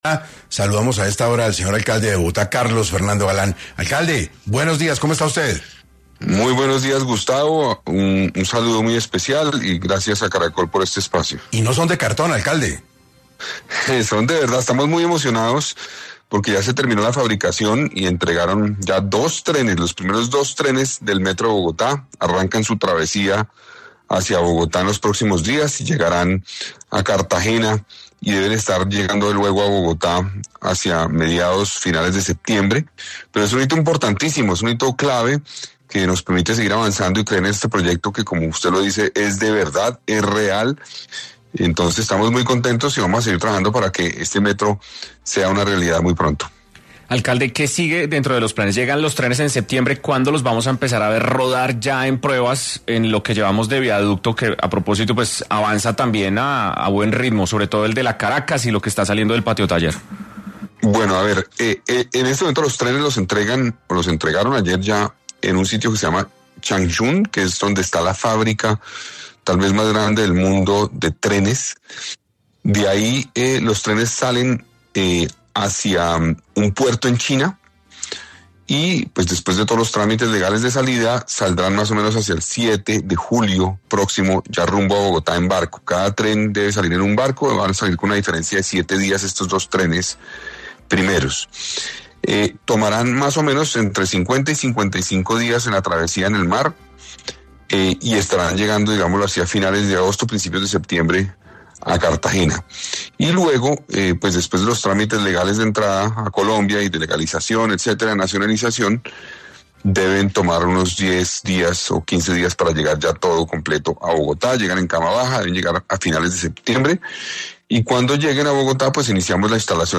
El alcalde de Bogotá habló en 6AM sobre el avance del Metro de la ciudad y en qué fecha se tiene estimado finalice la construcción completa del proyecto